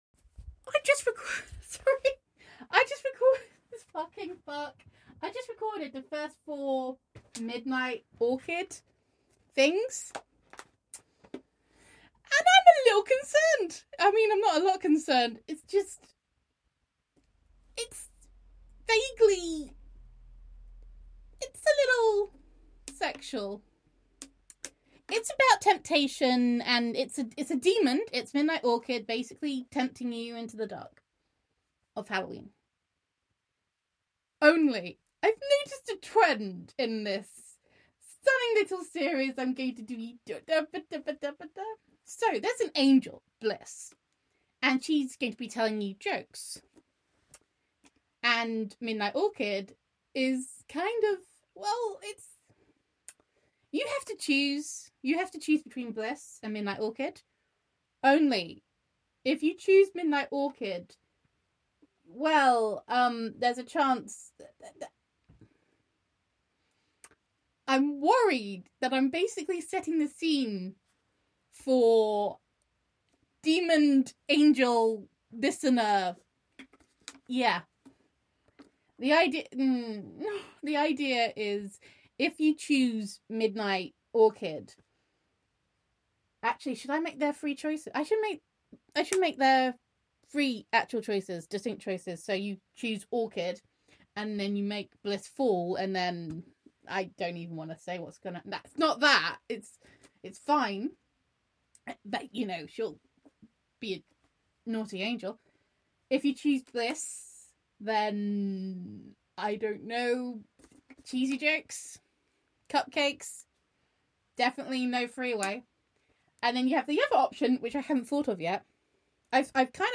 ramble